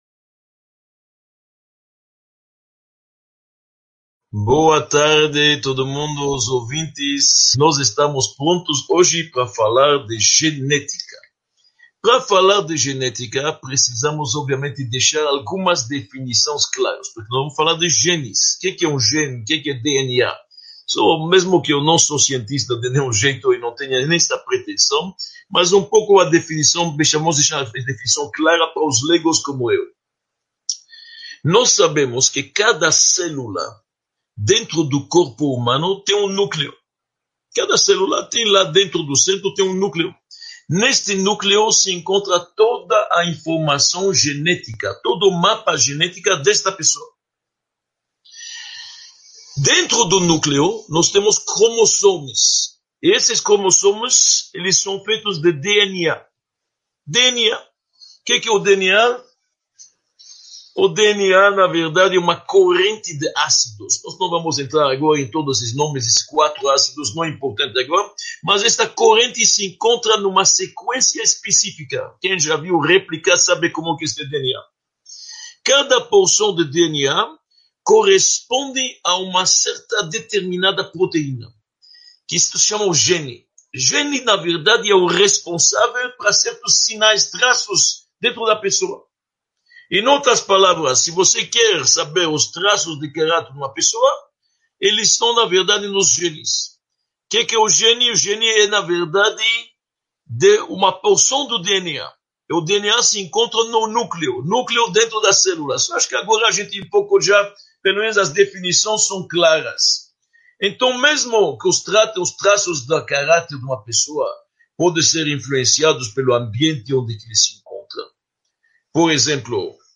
Aula 02